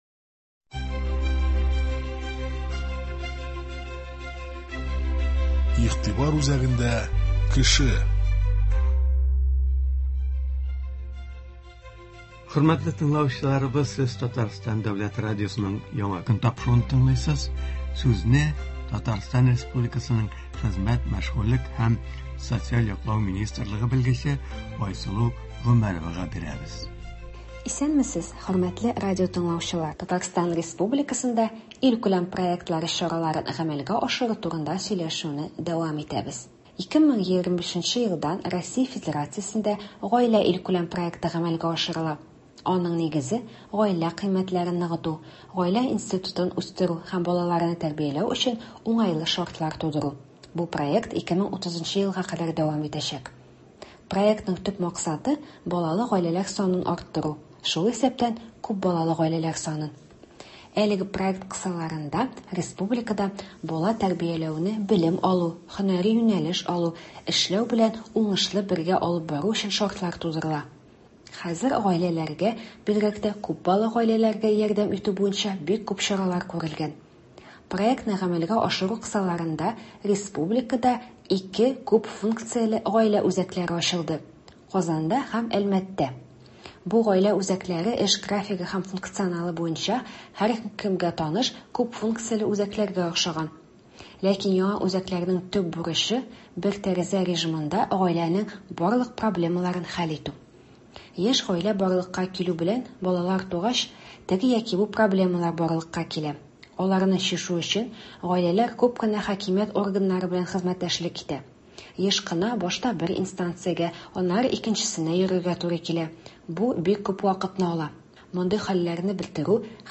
Туры эфир (26.01.26)